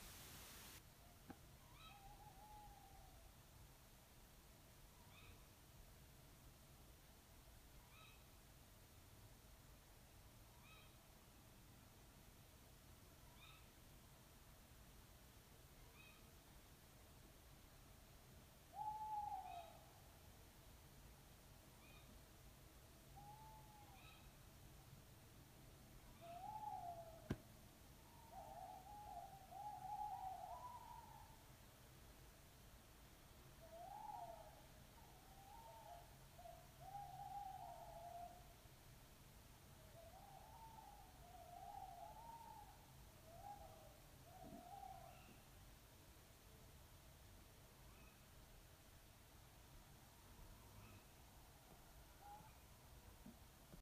Tawny owls calling at Faskine
It's 1am on a still night. The tawny owls of Faskine in Airdrie, Scotland, are declaring their territories. The other bird call is most likely to be a grey heron.